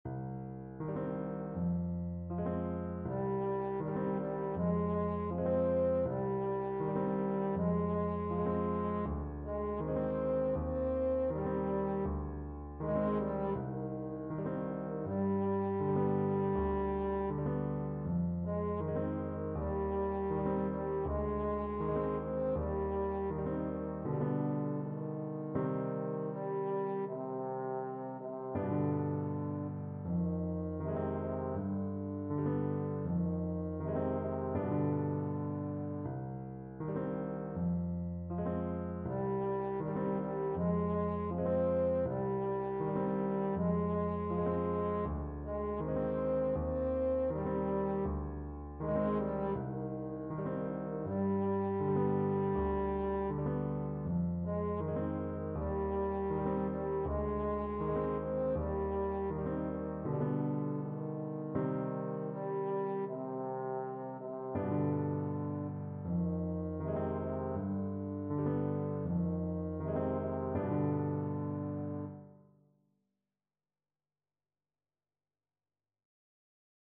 Traditional Trad. Chugoku Chiho no Komori-uta (Chugoku Region Lullaby) French Horn version
French Horn
Andante
4/4 (View more 4/4 Music)
C minor (Sounding Pitch) G minor (French Horn in F) (View more C minor Music for French Horn )
Traditional (View more Traditional French Horn Music)